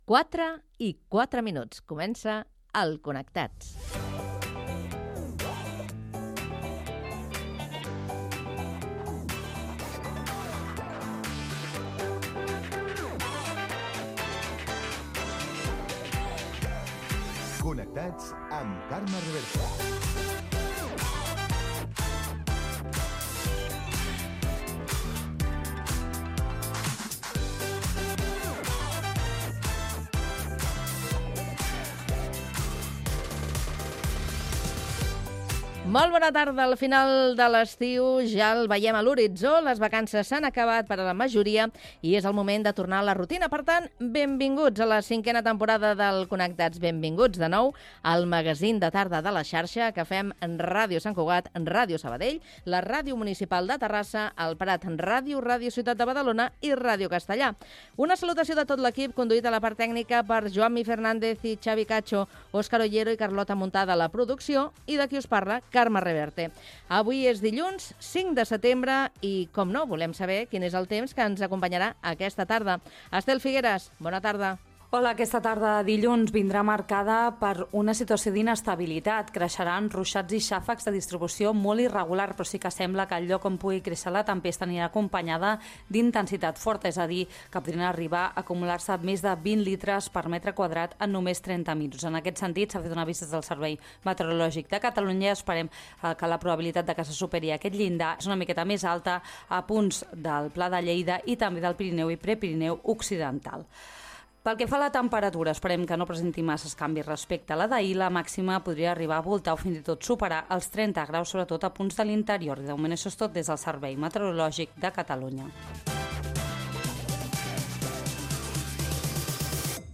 Hora, careta del programa, presentació, previsió meteorològica, sumari, indicatiu, ronda d'actualitat amb les emissores connectades.
Info-entreteniment